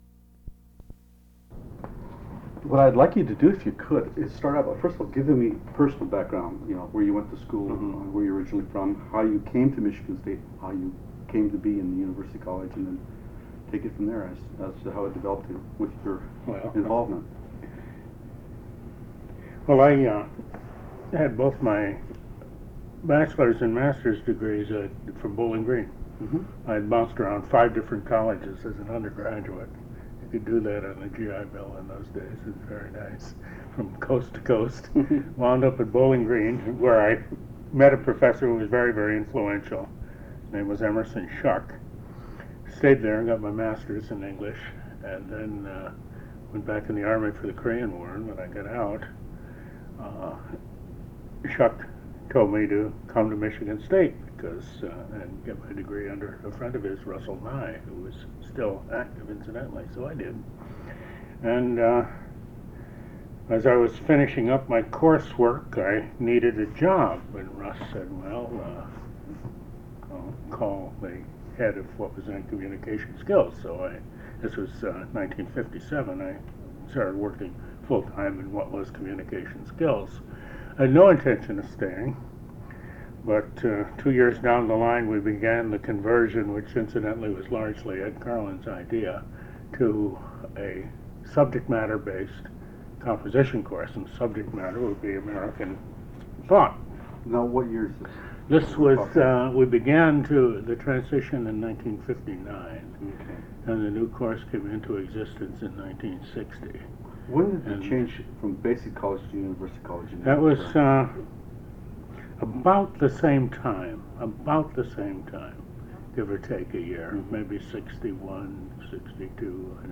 Subjects: Faculty
Date: June 26, 1990 Format: Audio/mp3 Original Format: Audio cassette tape Resource Identifier: A008652 Collection Number: UA 10.3.156 Language: English Rights Management: Educational use only, no other permissions given.